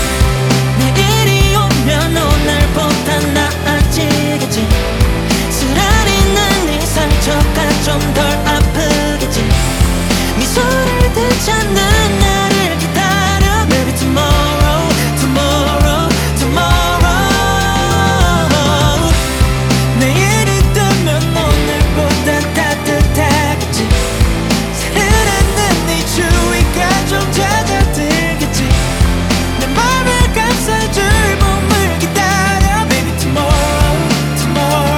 Скачать припев
K-Pop
2025-05-07 Жанр: Поп музыка Длительность